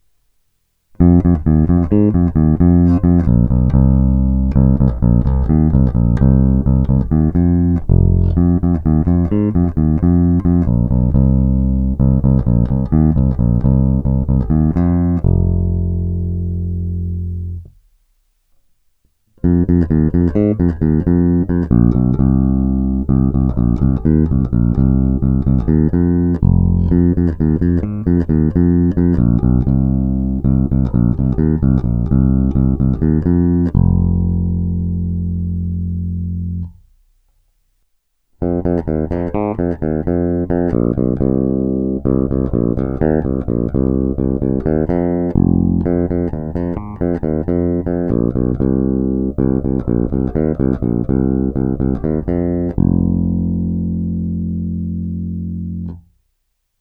Zvuk má očekávatelně moderní charakter, je pevný, konkrétní, vrčí, má ty správné středy důležité pro prosazení se v kapele.
Není-li uvedeno jinak, následující nahrávky jsou provedeny rovnou do zvukové karty a s korekcemi na středu a dále jen normalizovány, tedy ponechány bez postprocesingových úprav.